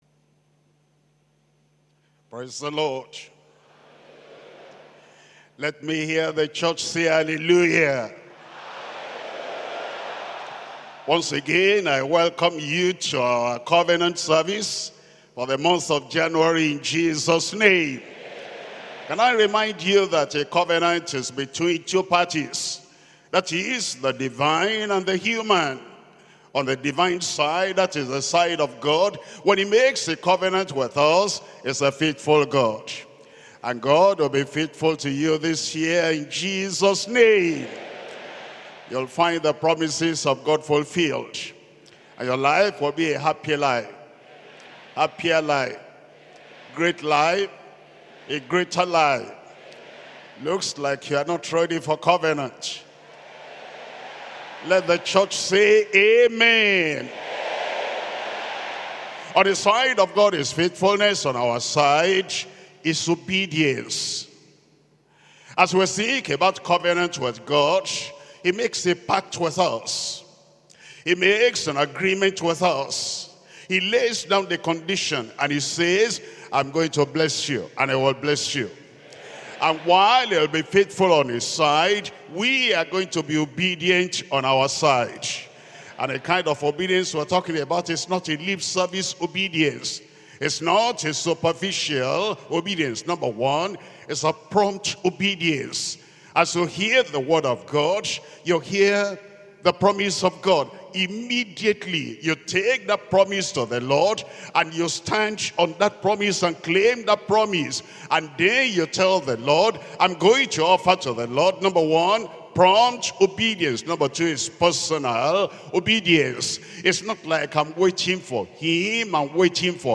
SERMONS – Deeper Christian Life Ministry Australia
2025 Covenant Service